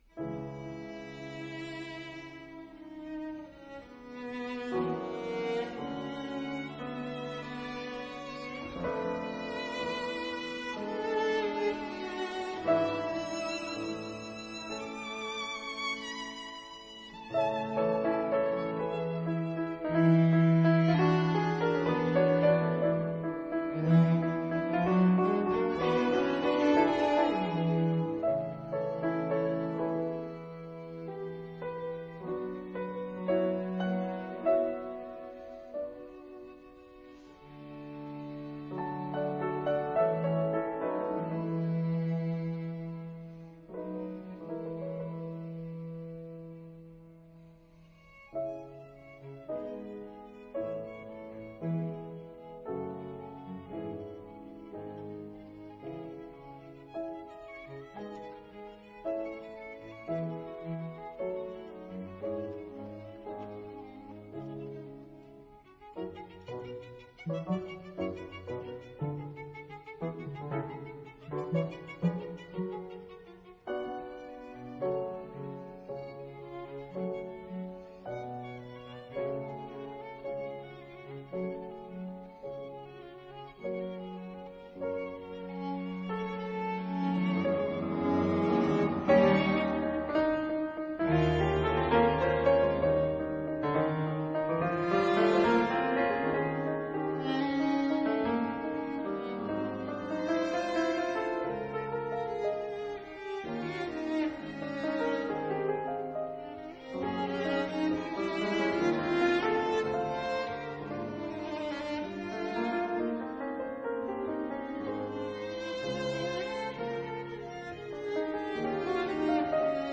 但優雅古典，動人自然，可以播放一整天。
其中的第二號，活潑又溫暖，重滿陽光。